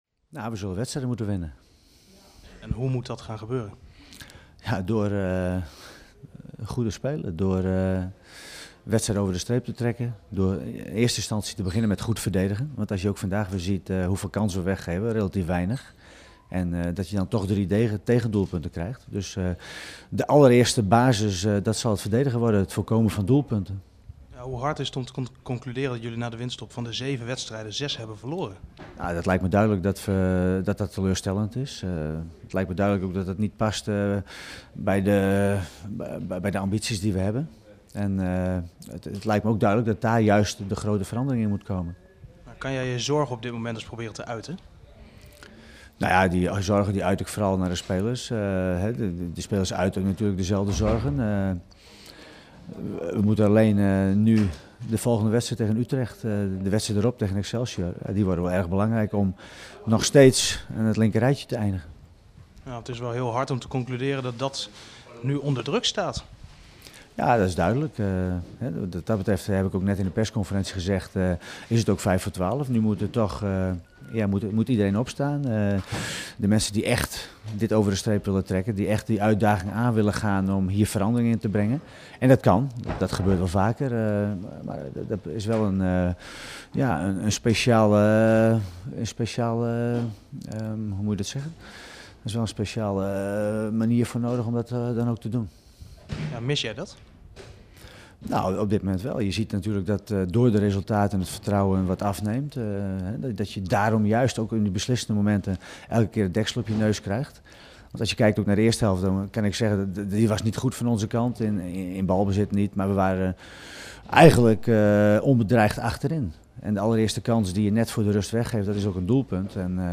in gesprek met Pieter Huistra